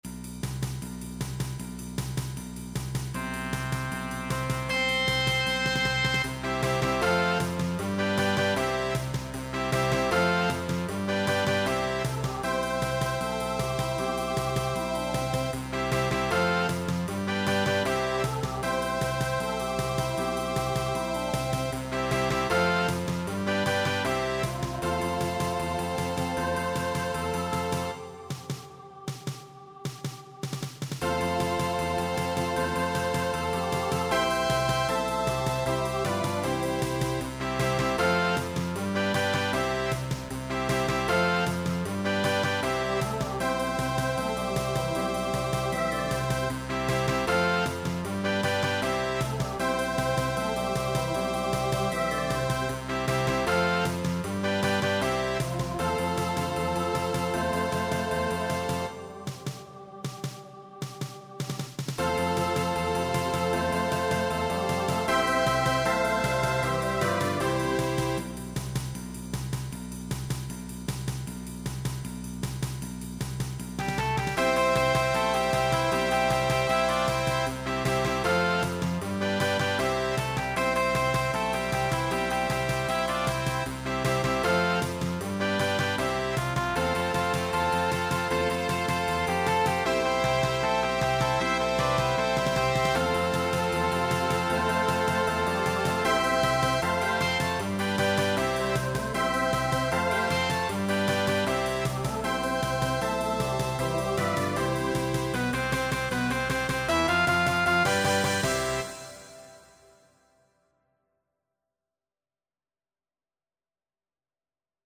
Voicing TTB Instrumental combo Genre Rock